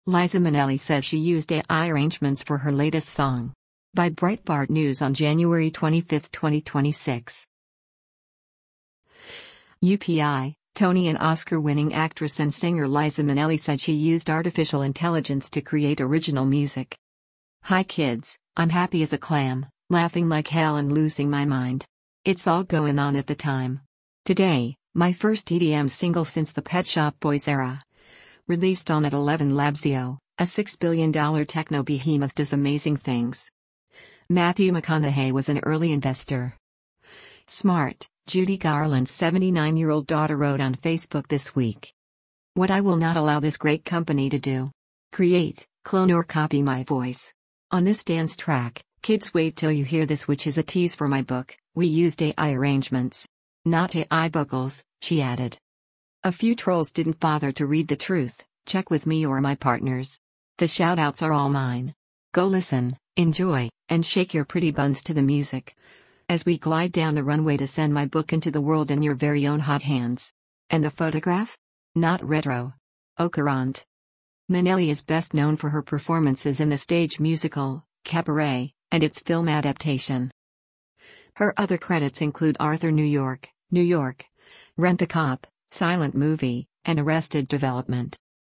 Not AI vocals,” she added.